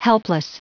Prononciation du mot helpless en anglais (fichier audio)
Prononciation du mot : helpless